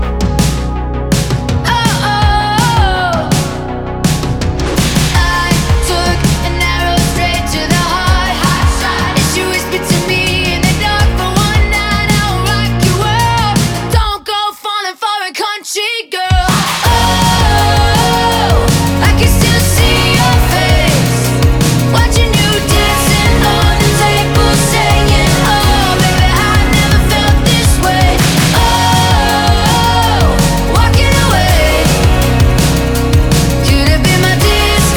Скачать припев
2025-05-15 Жанр: Поп музыка Длительность